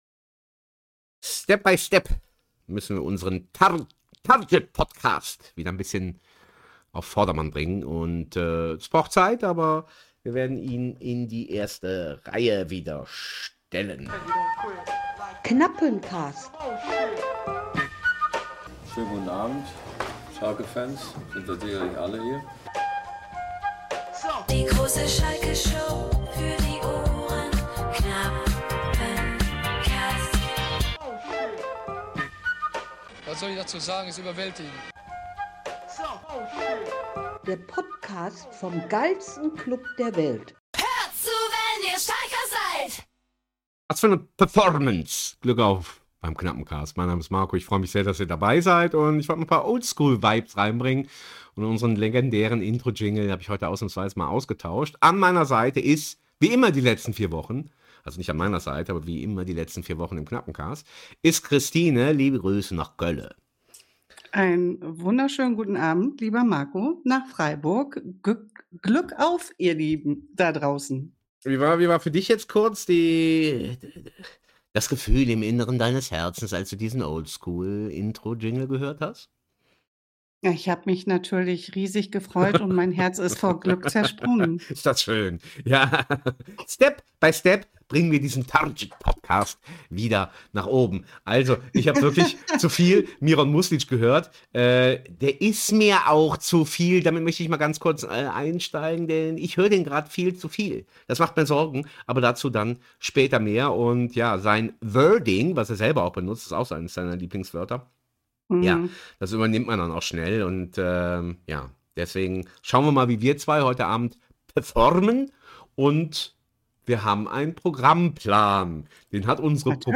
Transfermarkt-Check, legendäres Spiel, Ausblick aufs k(l)eine Derby. Dazu das übliche Knappencast-Halbwissen und Performance-Jingles.